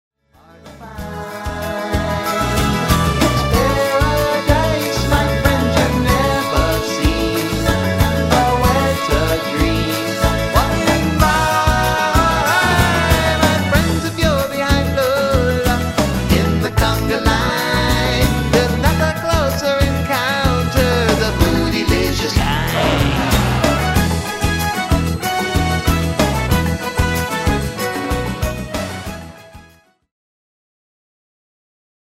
A WIDE VARIETY OF ROCK INCLUDING,